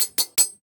smithing_table1.ogg